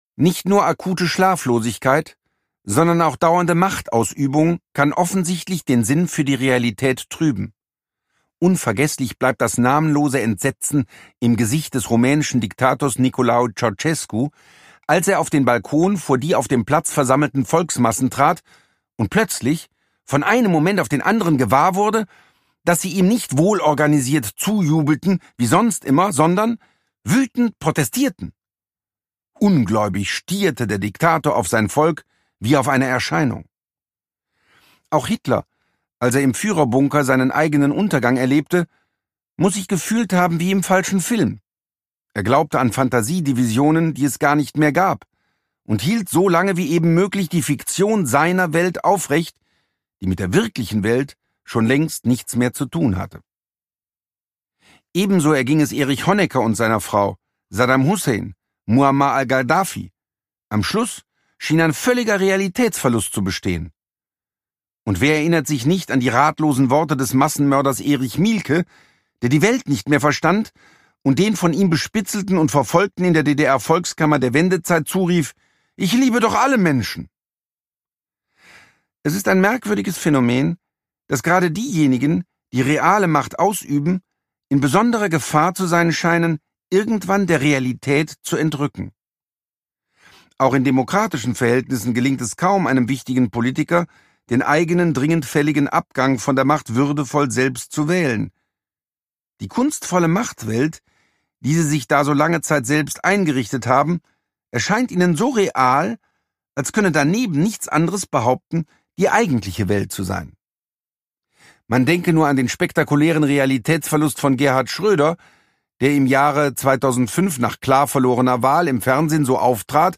Produkttyp: Hörbuch-Download
Fassung: Ungekürzte Lesung
Gelesen von: Manfred Lütz